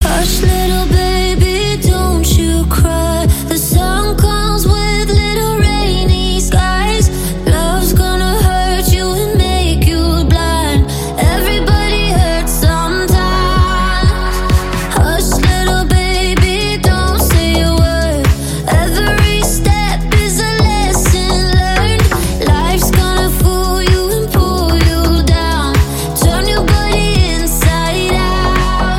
Приятный вокал